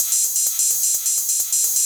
Index of /musicradar/ultimate-hihat-samples/128bpm
UHH_ElectroHatC_128-02.wav